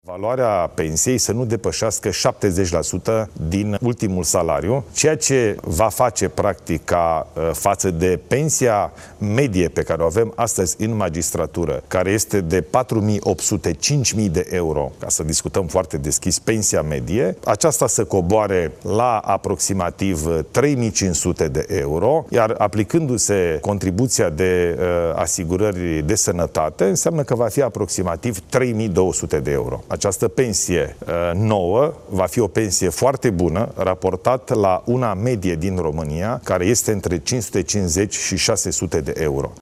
Chiar dacă magistrații au organizat proteste, prim-ministrul a declarat aseară la Antena3 că rămâne valabilă propunerea inițială: pensia să fie 70% din ultimul salariu net, și nu 80% din cel brut, așa cum este în prezent.